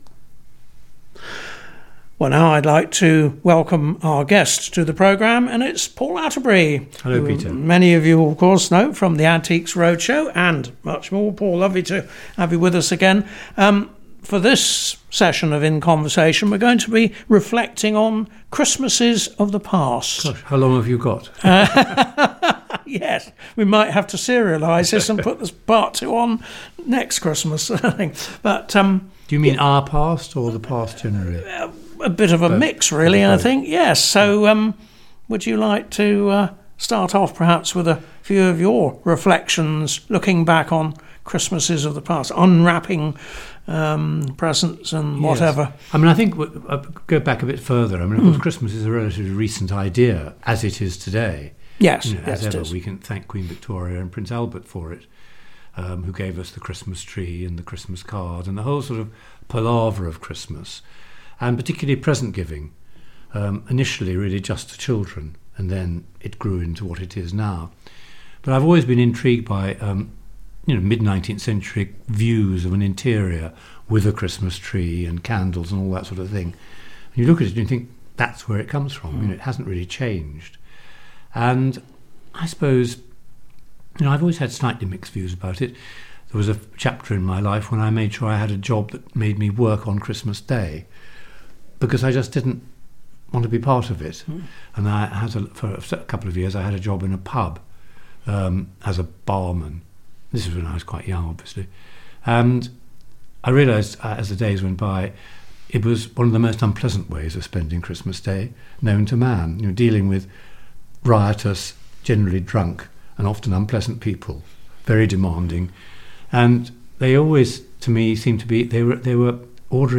A regular guest on KeeP 106 is Paul Atterbury, an antiques expert who has frequently appeared over many years on the BBC’s Antiques Roadshow.